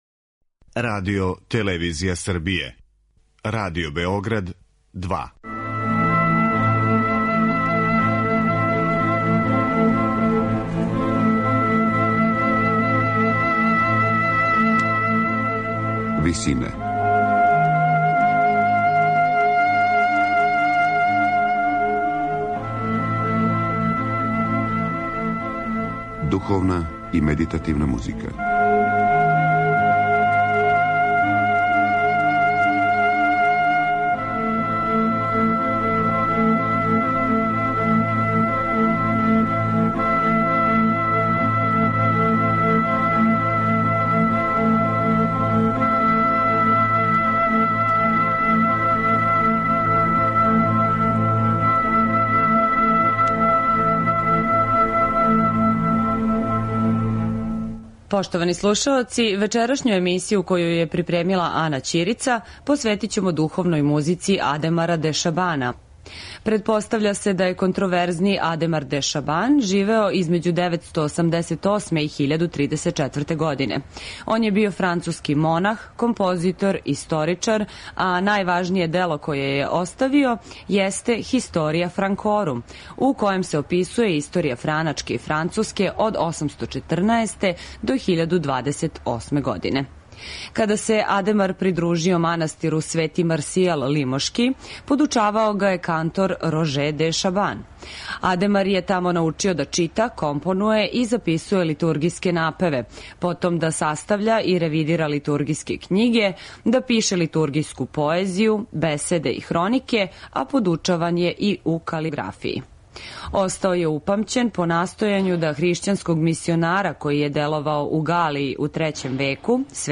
Духовна музика